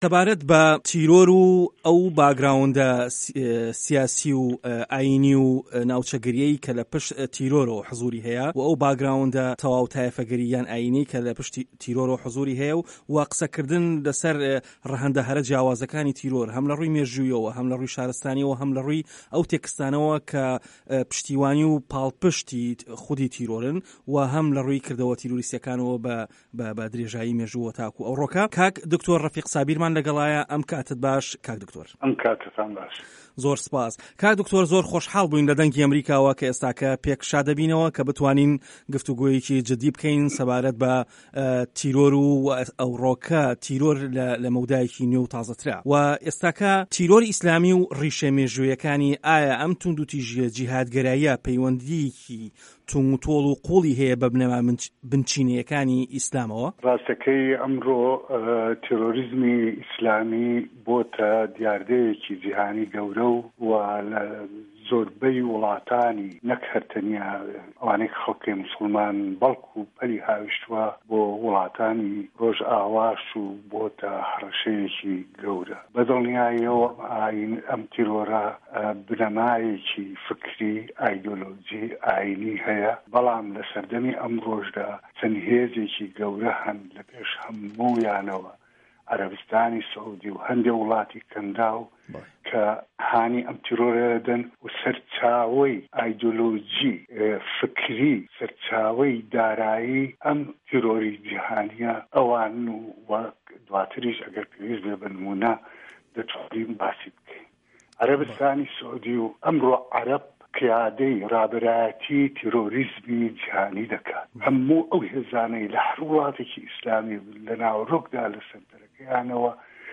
بەشی دووهەمی وتووێژی بەڕێز ڕەفیق سابیر